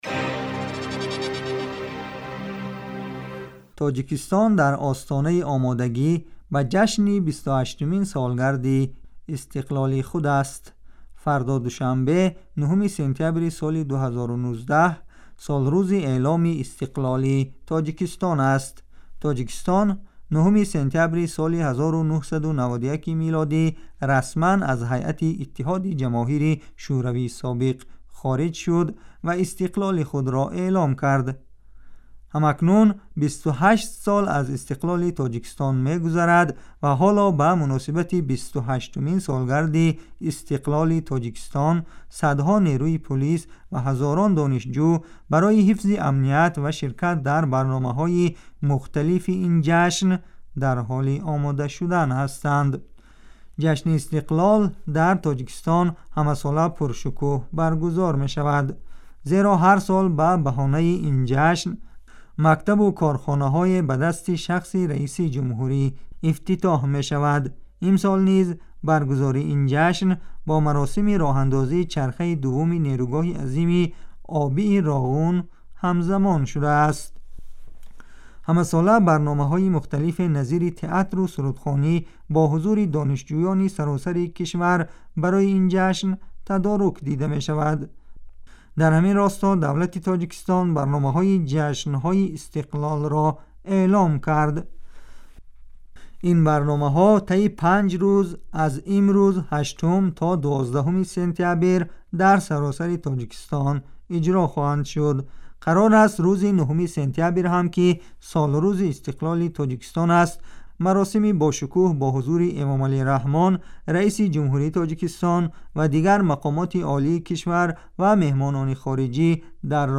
گزارش ویژه: 28 سالگی استقلال تاجیکستان